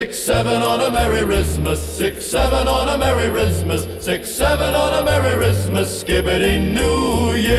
67 on a merry rizzmas Meme Sound Effect
Category: Meme Soundboard